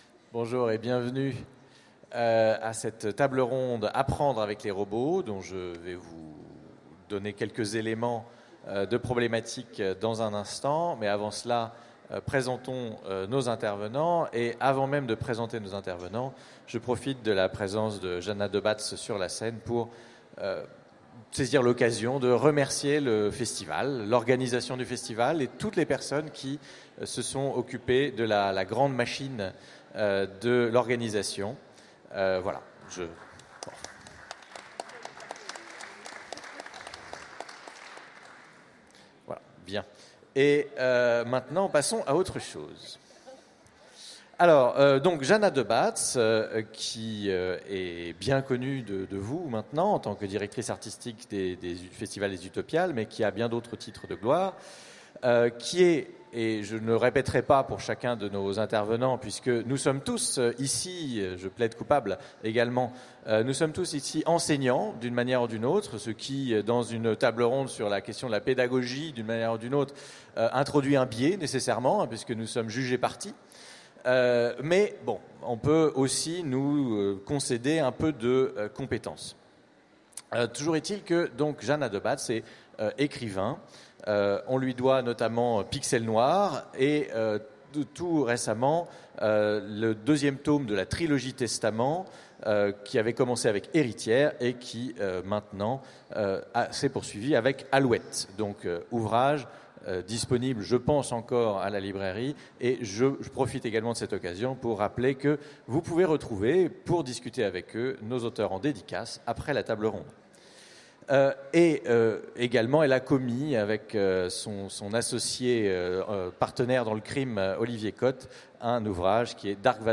Utopiales 2016 : Conférence Apprendre avec les robots